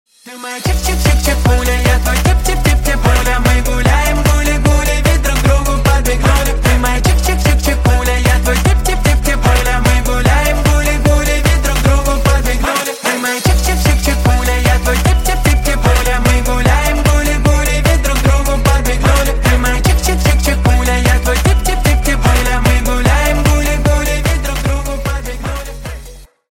Весёлые Рингтоны
Поп Рингтоны